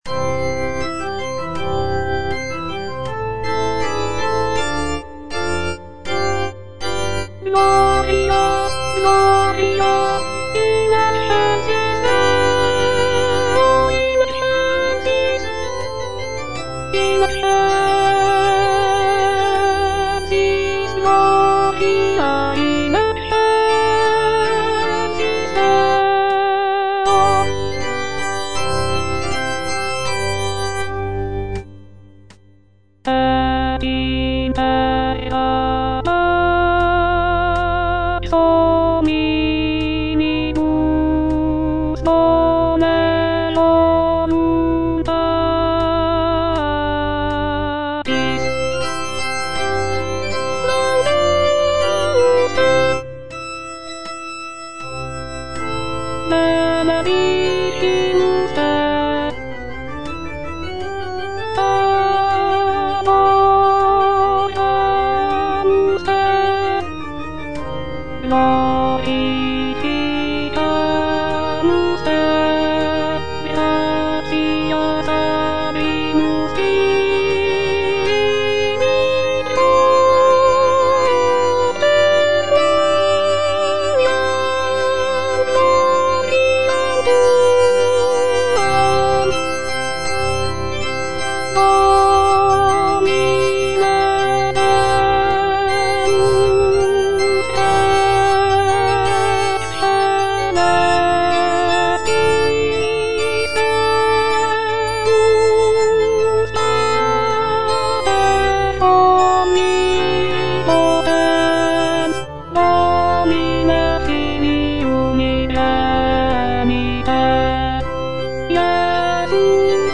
C.M. VON WEBER - MISSA SANCTA NO.1 Gloria - Alto (Voice with metronome) Ads stop: auto-stop